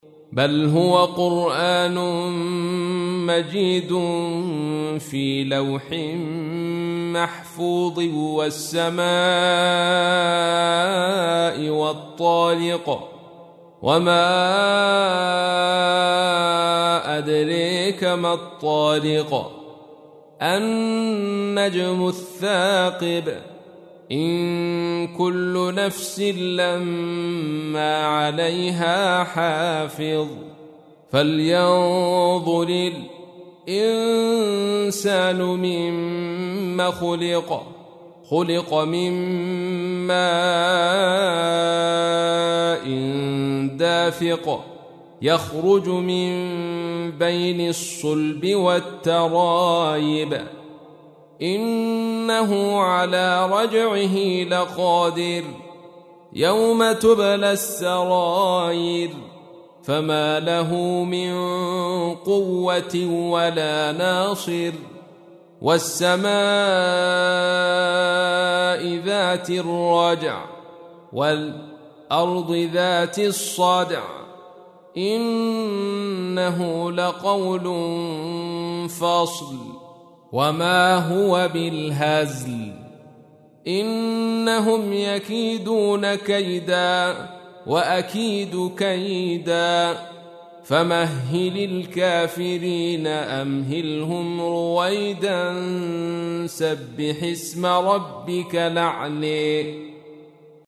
تحميل : 86. سورة الطارق / القارئ عبد الرشيد صوفي / القرآن الكريم / موقع يا حسين